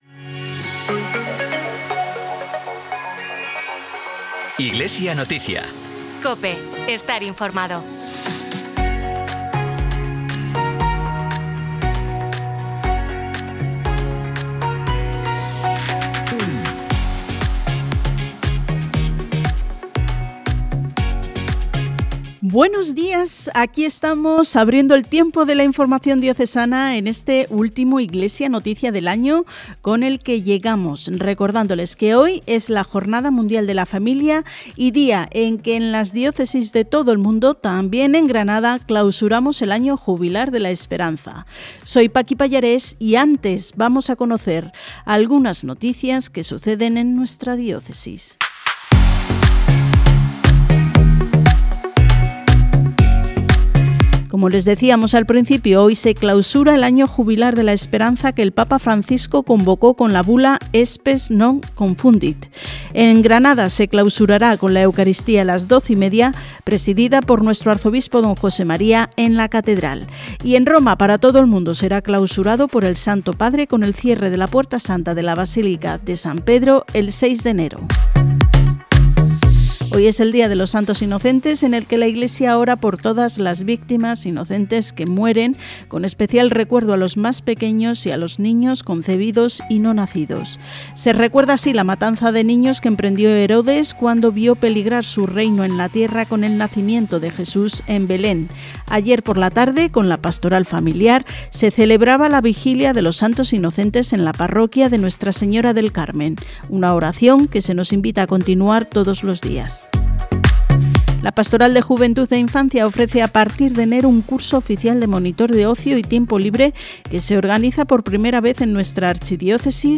Emitido en COPE Granada y COPE Motril el 28 de diciembre de 2025.